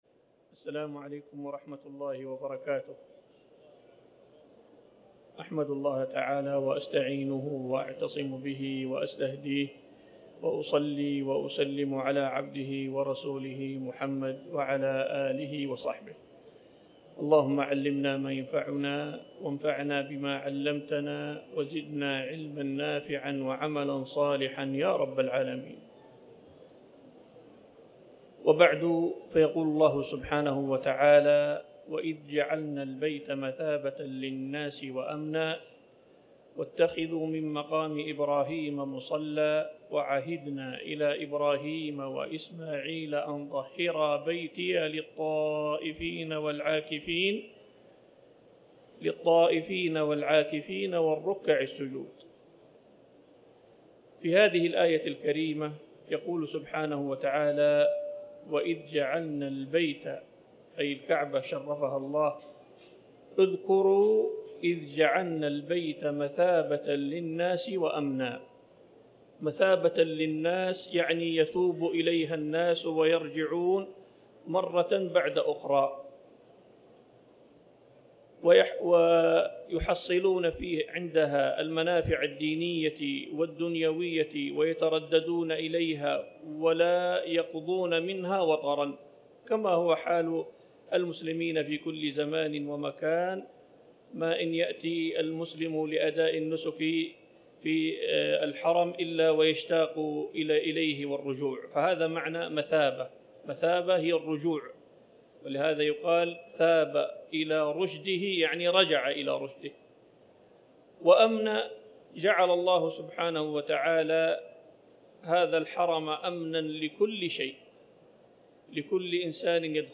4رمضان-محاضرة-أن-طهرا-بيتي-للطائفين-والعاكفين1-1.mp3